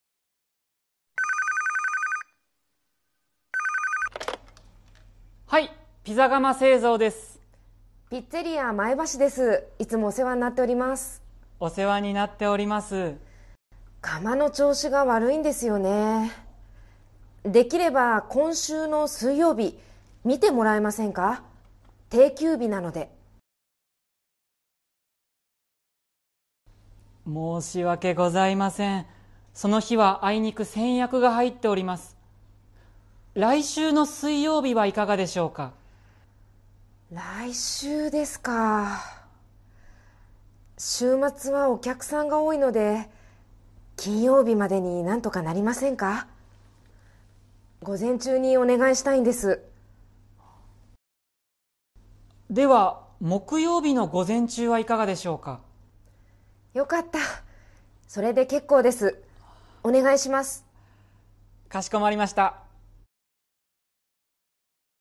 Role-play Setup
Conversation Transcript
skit24.mp3